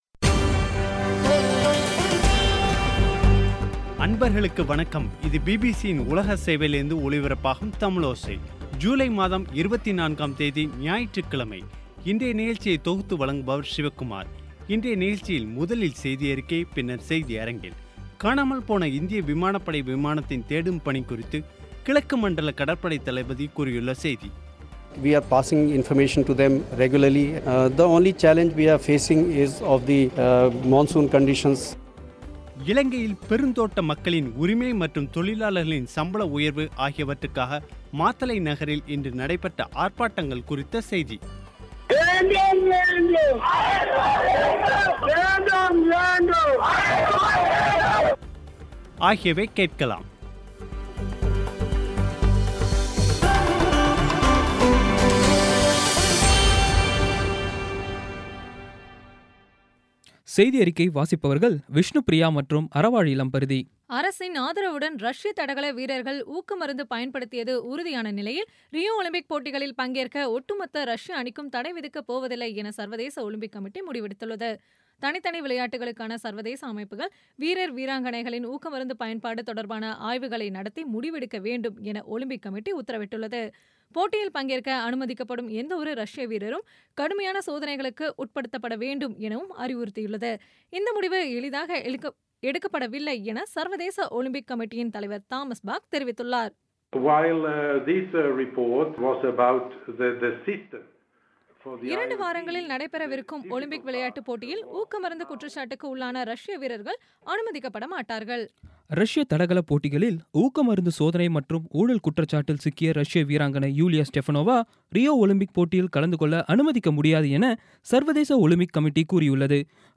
இன்றைய நிகழ்ச்சியில் முதலில் செய்தியறிக்கை, பின்னர் செய்தியரங்கில்